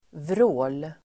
Uttal: [vrå:l]